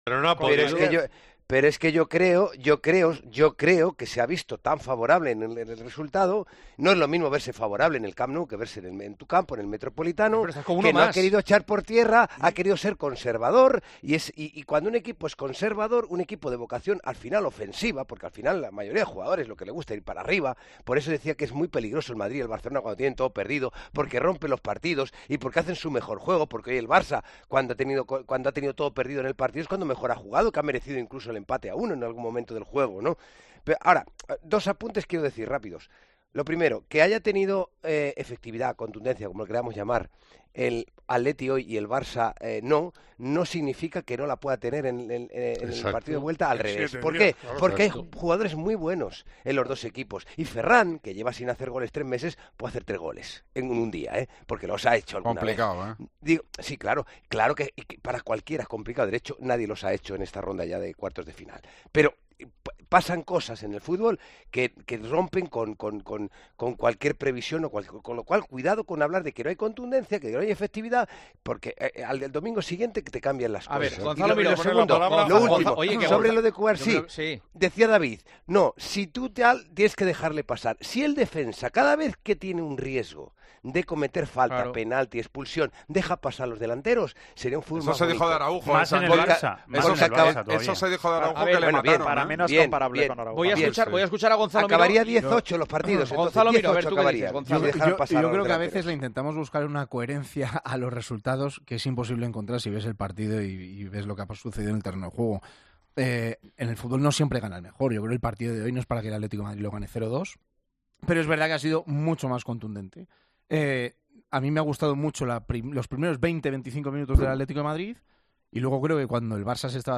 Juanma Castaño escucha la reflexión de Santi Cañizares y el resto de sus tertulianos sobre las posibilidades de remontada y la imprevisibilidad del fútbol